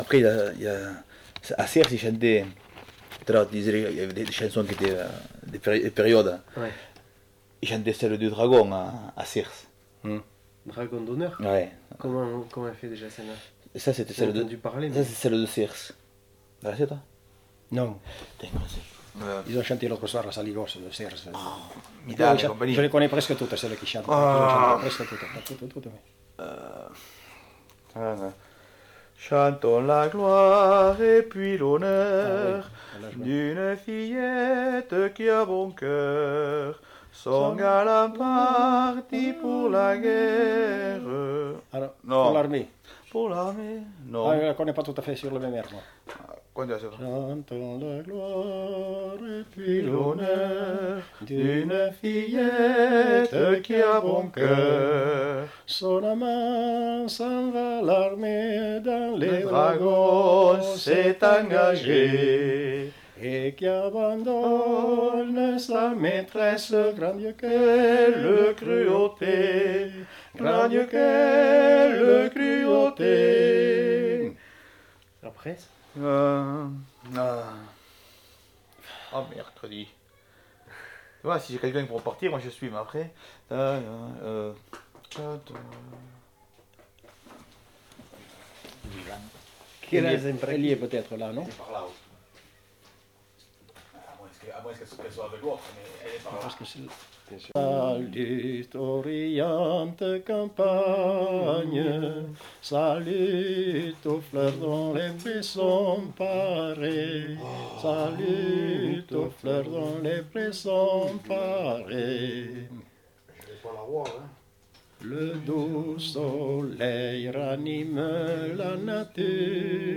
Aire culturelle : Bigorre
Lieu : Villelongue
Genre : chant
Effectif : 2
Type de voix : voix d'homme
Production du son : chanté
Descripteurs : polyphonie
Notes consultables : Interprété en deux fois.